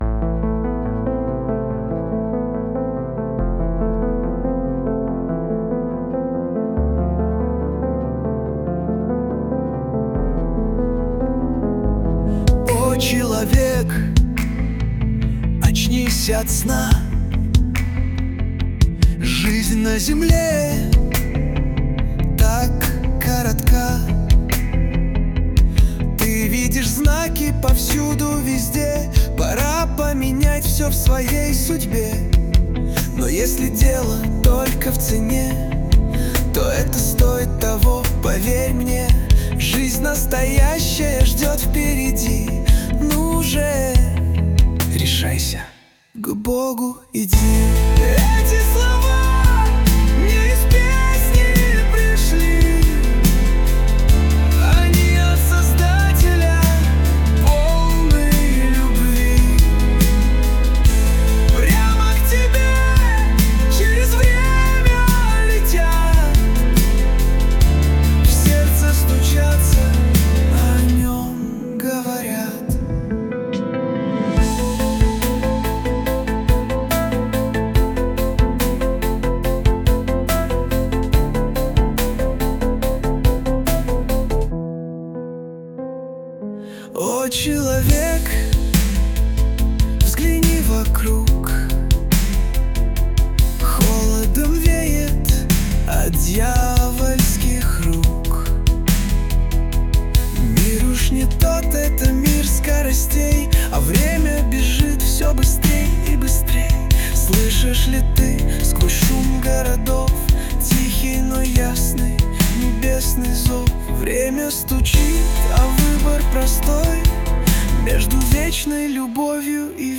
песня ai
220 просмотров 864 прослушивания 69 скачиваний BPM: 71